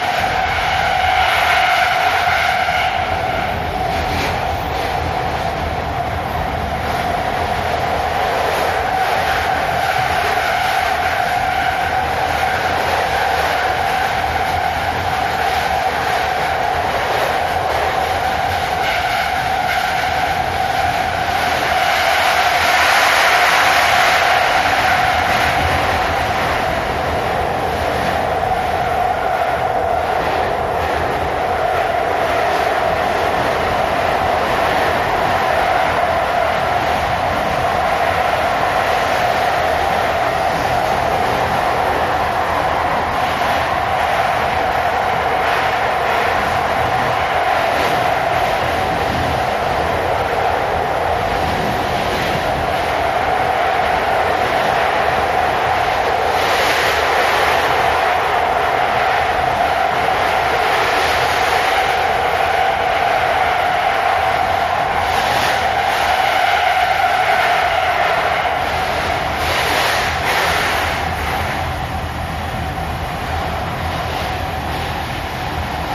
220828 [走行音] 仙山線 奥新川→面白山高原
宮城と山形の県境の仙山トンネルが爆音すぎたｗｗｗｗｗ
スーパー白鳥で通った青函トンネル内でもこれと似たような音してましたね